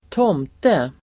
Ladda ner uttalet
Folkets service: tomte tomte substantiv, Santa Claus , brownie Uttal: [²t'åm:te] Böjningar: tomten, tomtar Synonymer: jultomte Definition: en sagofigur som vakar över en gård; jultomte brownie substantiv, tomte
tomte.mp3